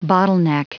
Prononciation du mot bottleneck en anglais (fichier audio)
Prononciation du mot : bottleneck